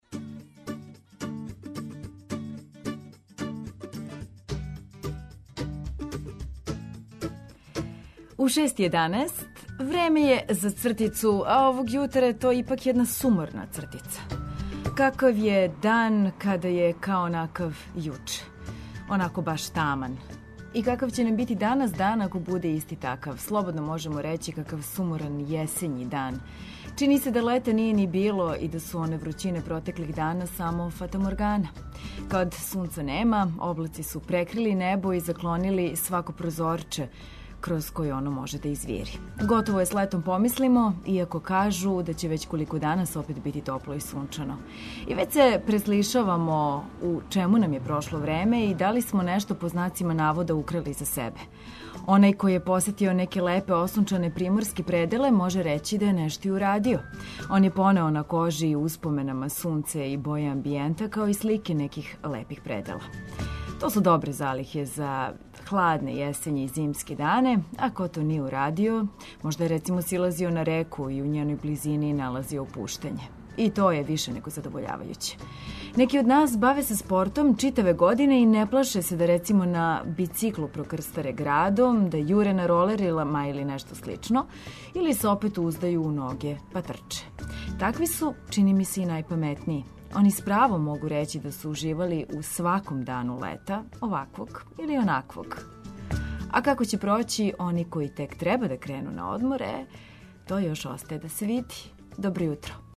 Наш репортер прати како протичу радови у Булевару деспота Стефана у Београду и распитује се зашто чак 11 линија ГСП „Београд” ових дана вози измењеним трасама.